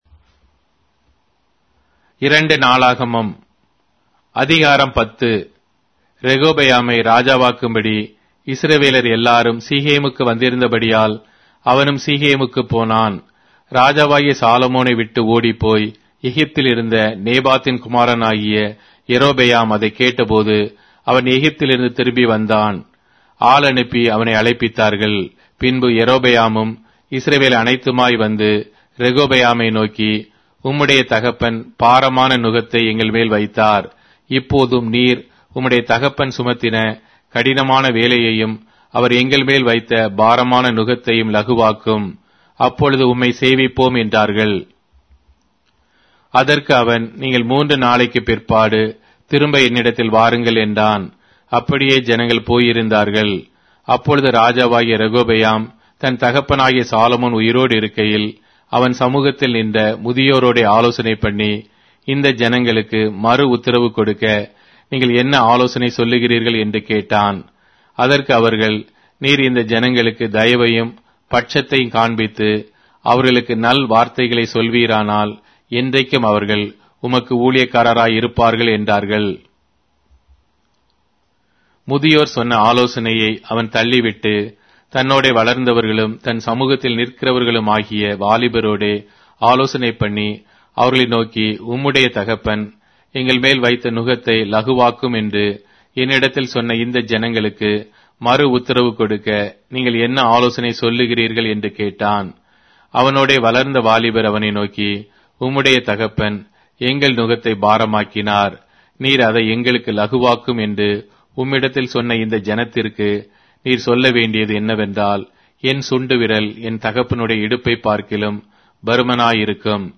Tamil Audio Bible - 2-Chronicles 19 in Ervmr bible version